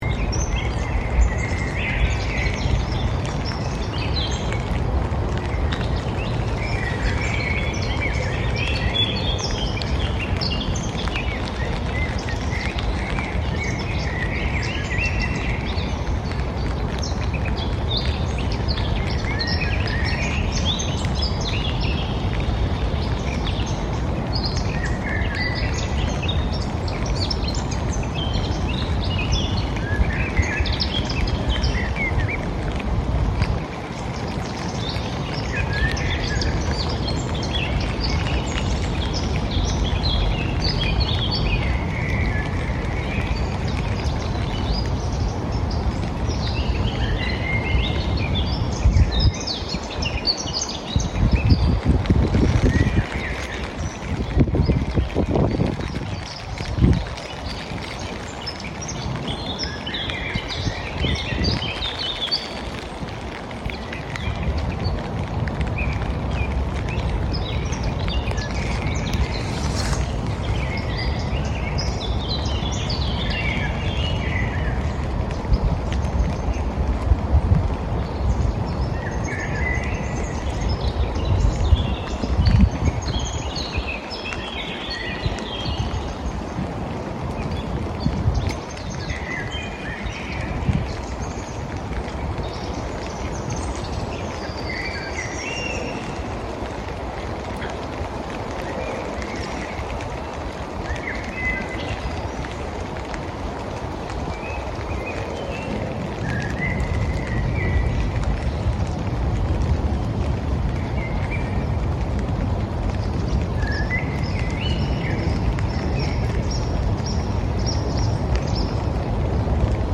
Meanwhile, behind the voices of birds, you can hear the constant sound of airplanes passing overhead on their way to Schiphol Airport, which was built on reclaimed land just like the forest. I recorded this on my way home from work on a day when spring rain had just begun to fall.